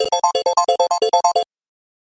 • Качество: 130, Stereo